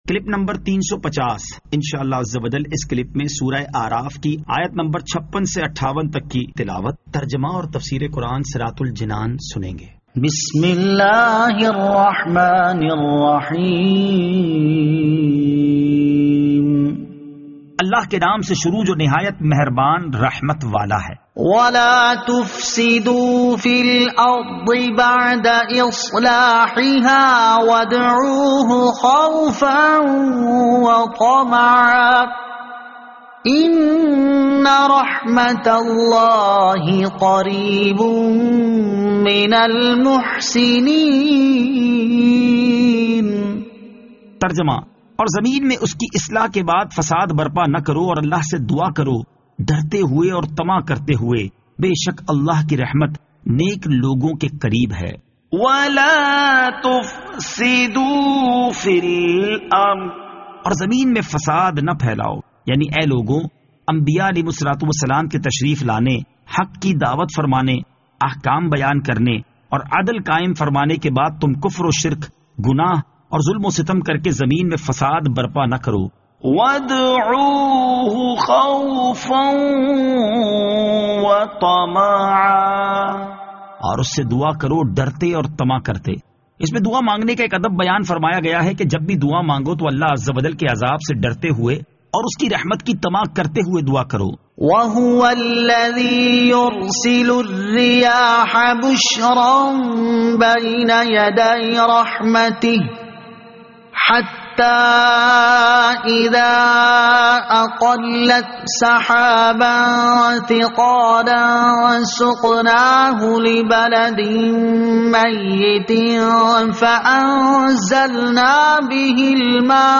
Surah Al-A'raf Ayat 56 To 58 Tilawat , Tarjama , Tafseer